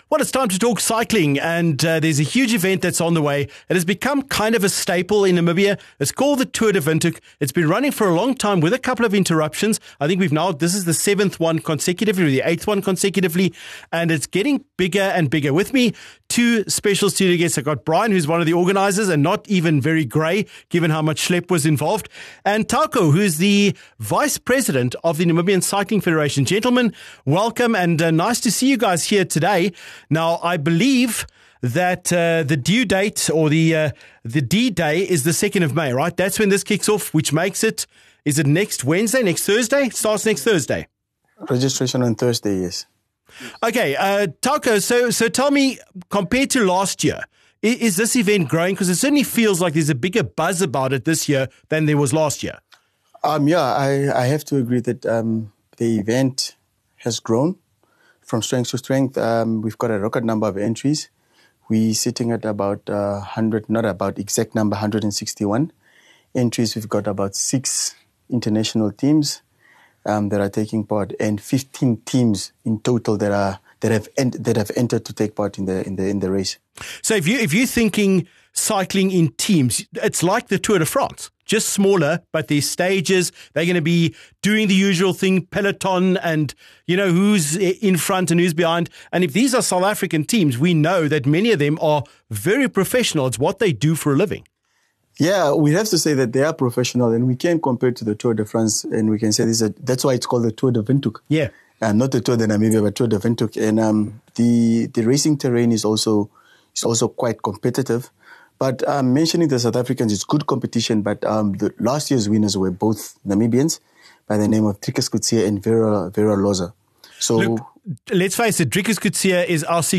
25 Apr Tour de Windhoek just days away! (Full interview)